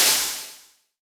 Roland.Juno.D _ Limited Edition _ Brush Kit _ Noise.wav